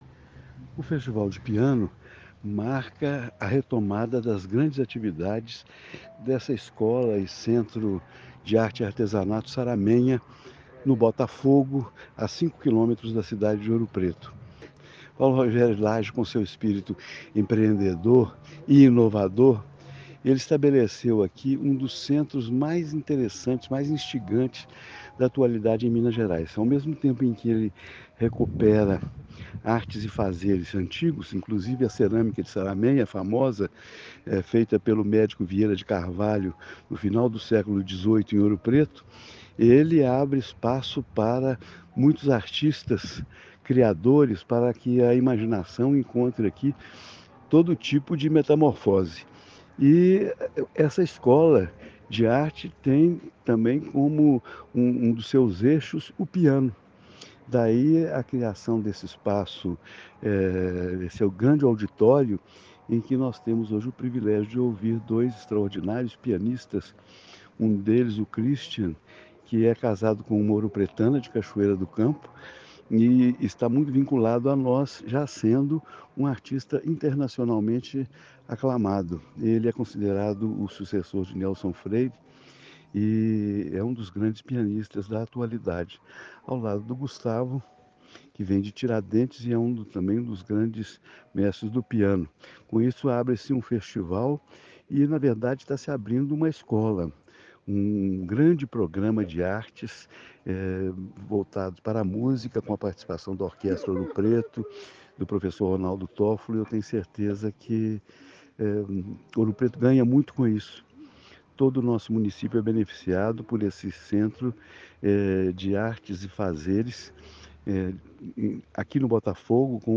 Angelo Oswaldo de Araújo Santos é prefeito de Ouro Preto e já exerceu os cargos de ministro interino e secretário de Estado de Cultura de Minas Gerais
Áudio: Prefeito Angelo Oswaldo fala sobre o Festival e a Escola Saramenha de Artes e Ofícios:
1-Angelo-Oswaldo-fala-sobre-a-abertura-do-I-Festival-de-Piano.mp3